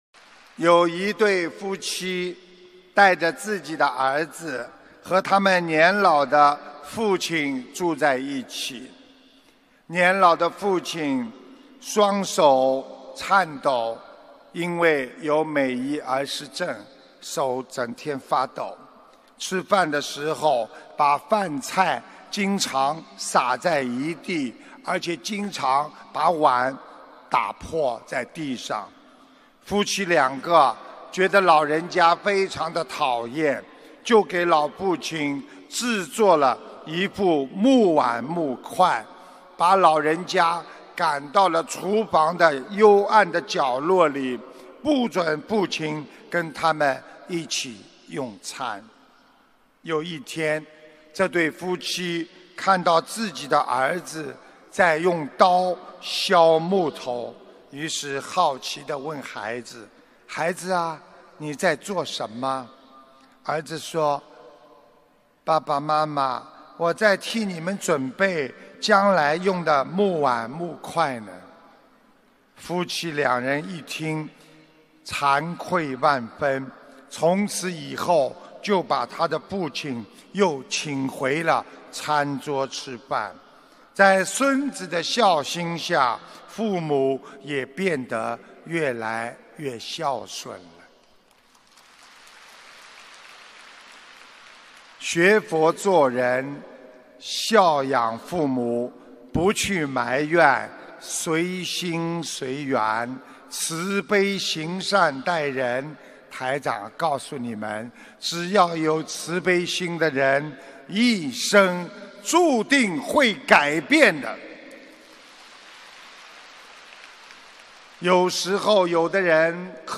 音频：为父母准备的木碗木块·师父讲小故事大道理